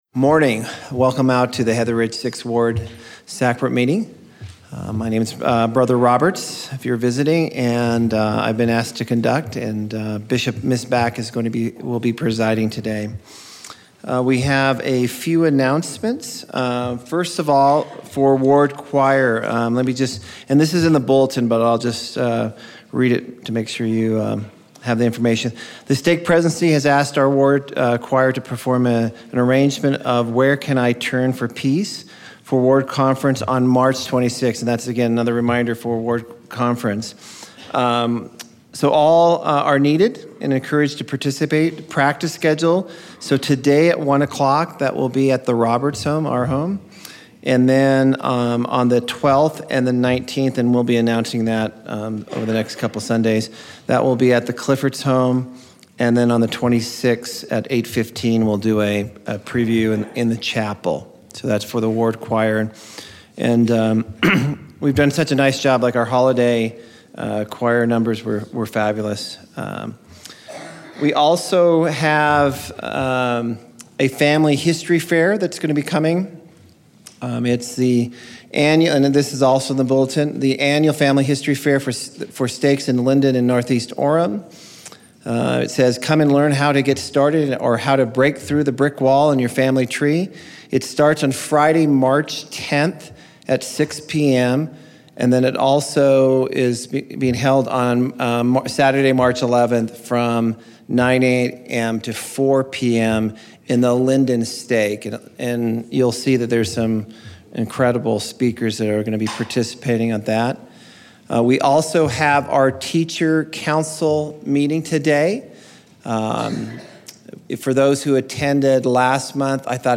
Heatheridge 6th Ward Sacrament Meeting 03/12/17